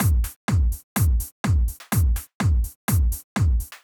Drumloop 125bpm 07-C.wav